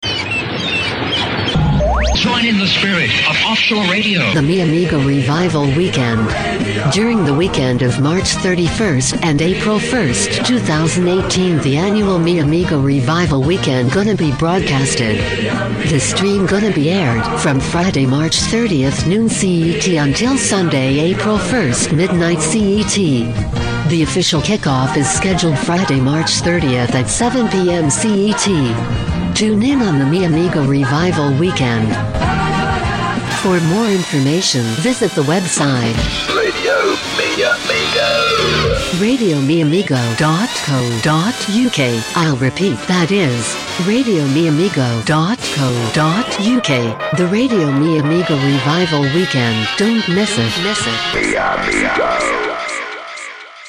Promo for the Radio Mi Amigo Revival Weekend